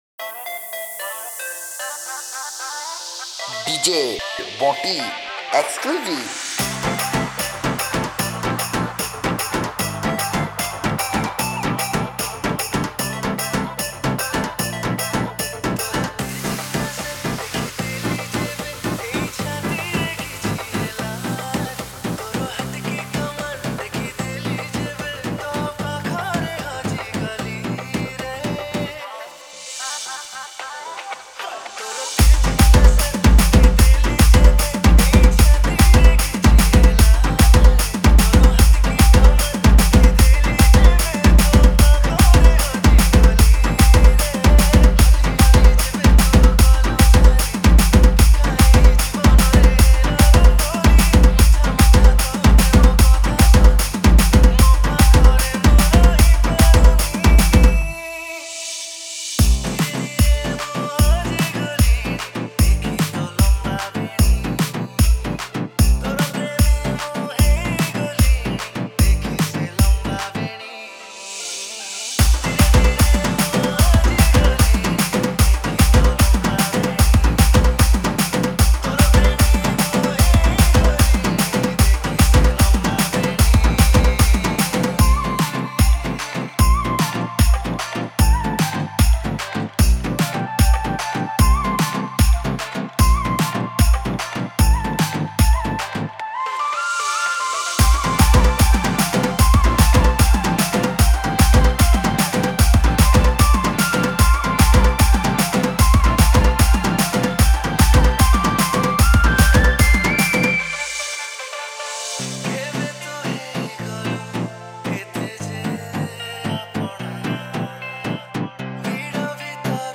Category: Odia New Dj Song 2024